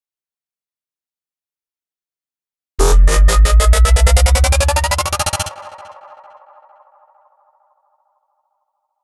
FX
Mech-FX-2.wav